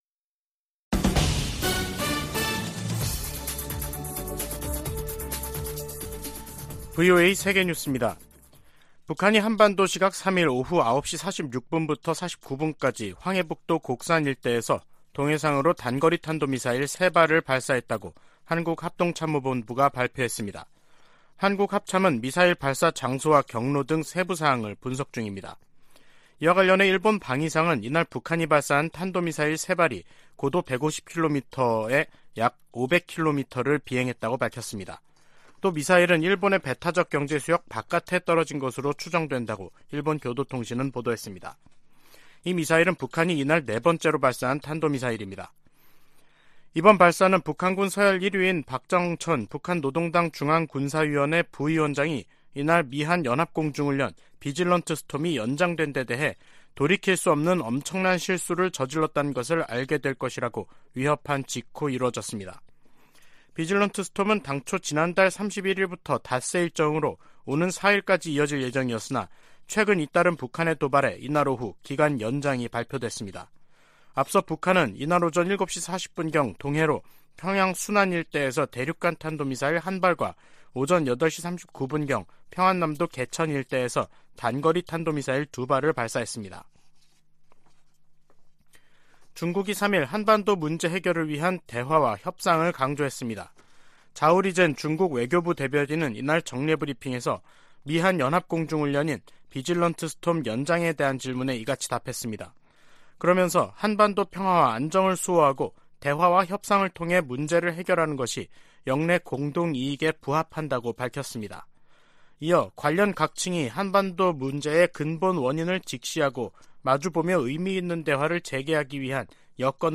VOA 한국어 간판 뉴스 프로그램 '뉴스 투데이', 2022년 11월 3일 3부 방송입니다. 북한 김정은 정권이 어제 동해와 서해상에 미사일과 포탄을 무더기로 발사한 데 이어 오늘은 대륙간탄도미사일, ICBM을 쏘면서 도발 수위를 높였습니다. ICBM 발사는 실패한 것으로 추정된 가운데 미국과 한국 정부는 확장 억제 실행력을 높이면서 북한의 어떠한 위협과 도발에도 연합방위태세를 더욱 굳건히 할 것을 거듭 확인했습니다.